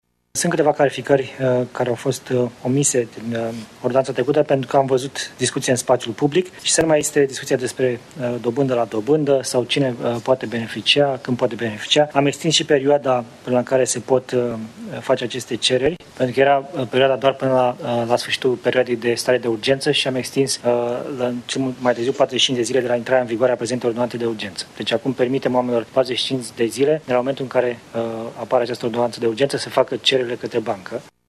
Guvernul va modifica ordonanța care prevede posibilitatea amânării ratelor, astfel încât la creditele ipotecare să nu fie aplicată „dobânda la dobândă” pentru lunile în care creditul este amânat, a anunțat ministrul Finanțelor, Florin Câțu în cadrul ședinței de Guvern din această după masă.